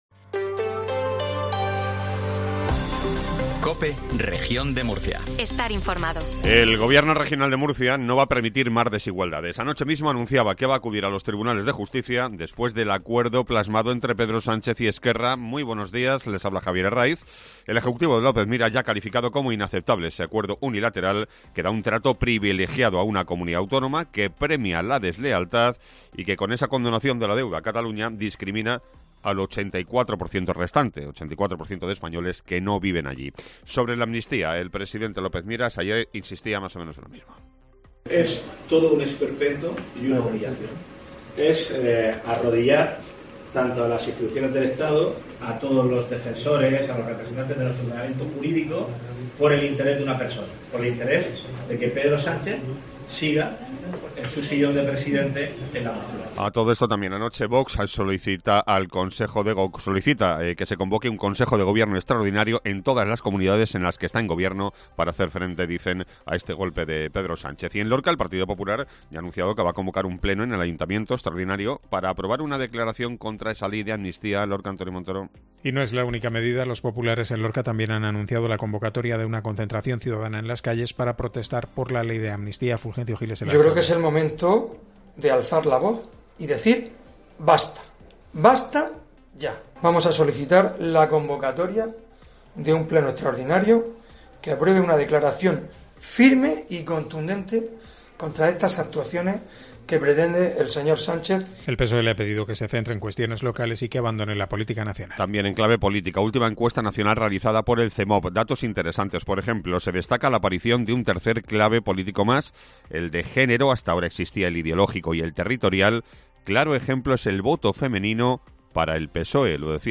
INFORMATIVO MATINAL COPE REGION DE MURCIA